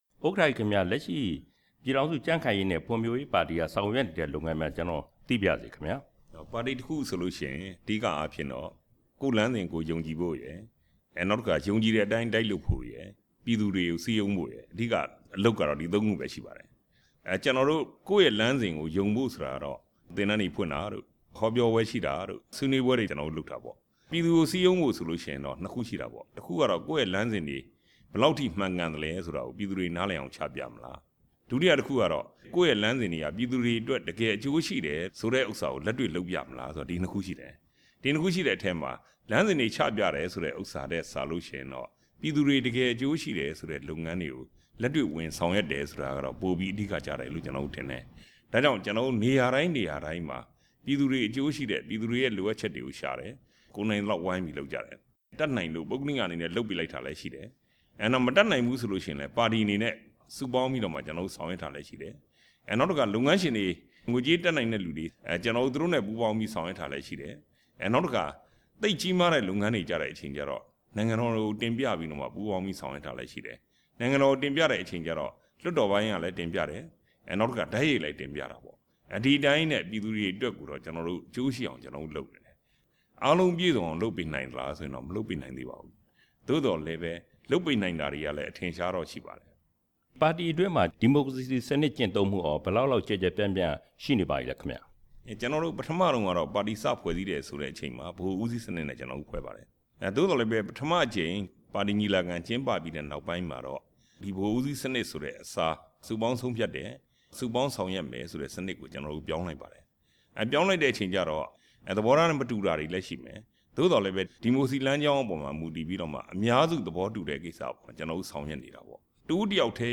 ပြည်ခိုင်ဖြိုးပါတီ ဒုတိယဥက္ကဌနဲ့ သီးသန့်တွေ့ဆုံမေးမြန်းချက်